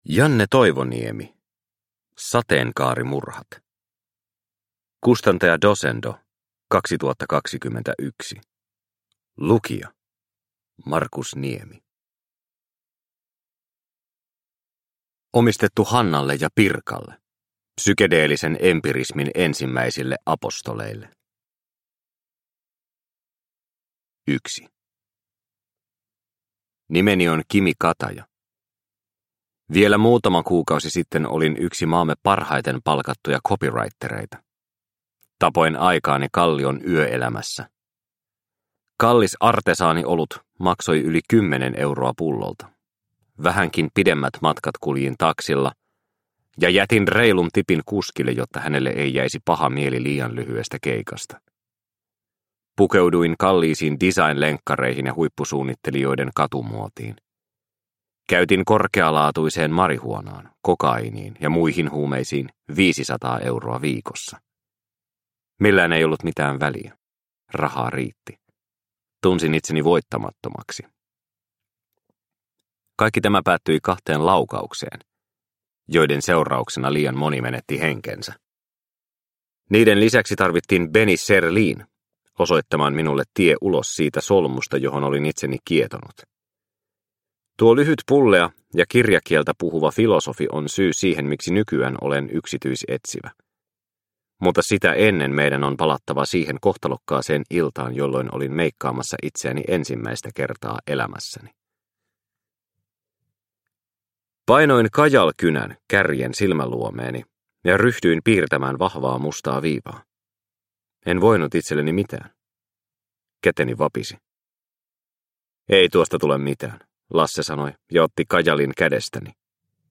Sateenkaarimurhat – Ljudbok – Laddas ner